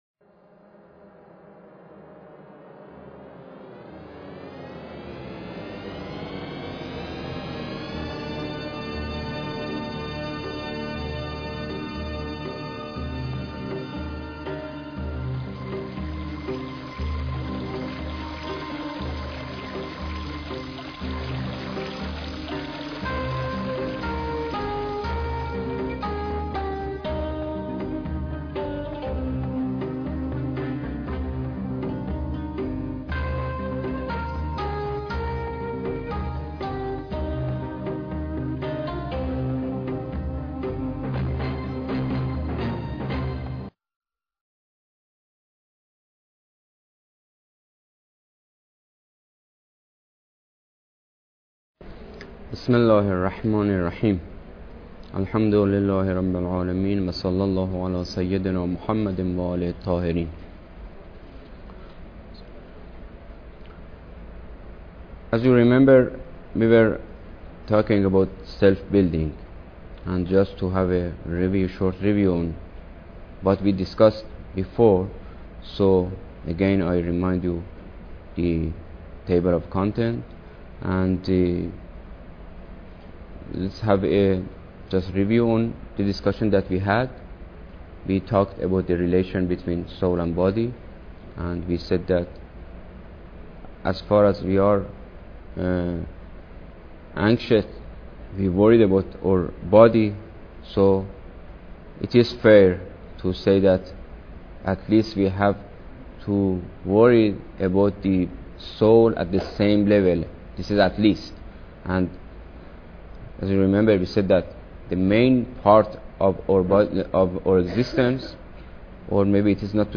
Lecture_4